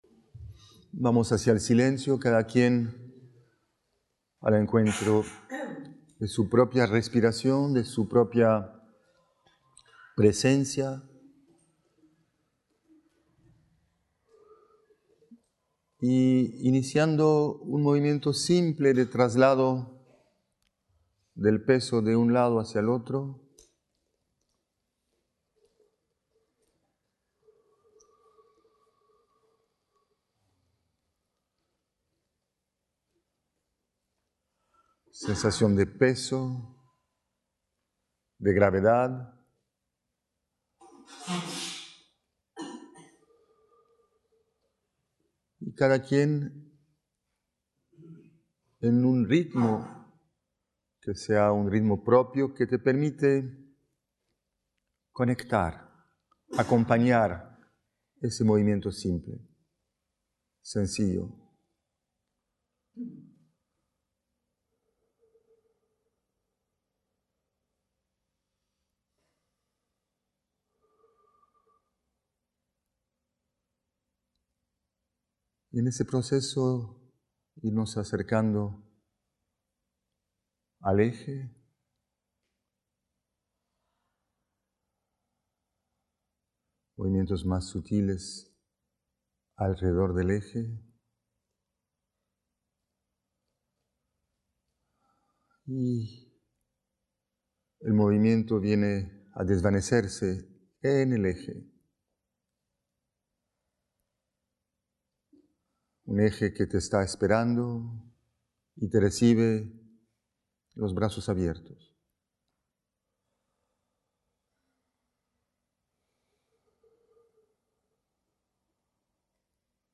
Videos y audios Una corta práctica guiada: en movimiento y en quietud, con un fino despertar atencional se habita el cuerpo y sus paisajes sensoriales. https